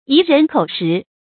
貽人口實 注音： ㄧˊ ㄖㄣˊ ㄎㄡˇ ㄕㄧˊ 讀音讀法： 意思解釋： 貽：給；口實：話柄；即可讓人利用的口實。